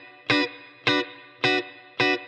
DD_TeleChop_105-Bmin.wav